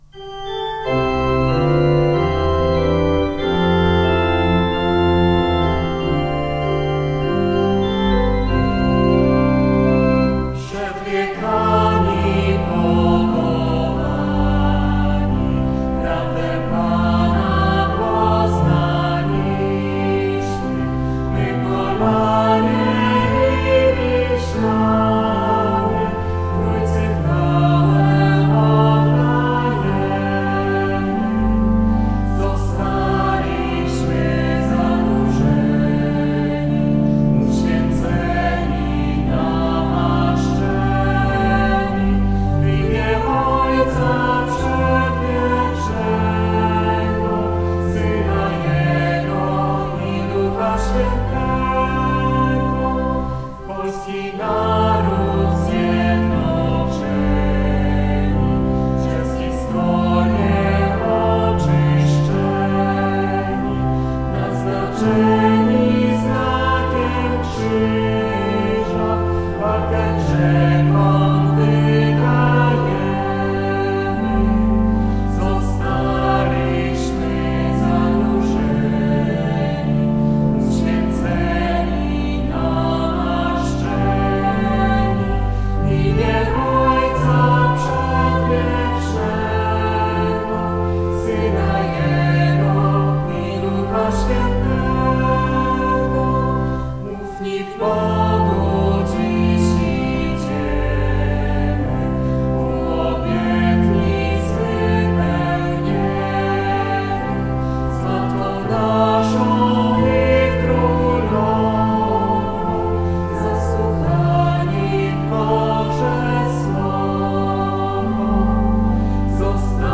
hymn na 1050. rocznicę Chrztu Polski